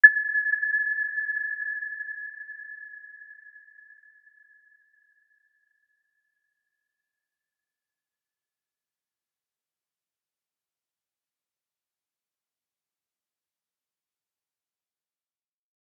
Aurora-B6-mf.wav